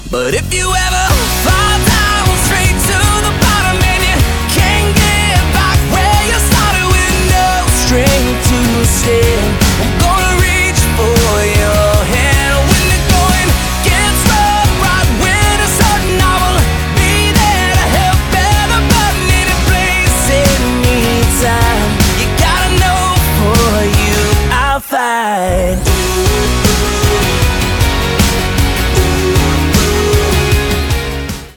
Красивая мелодичная песня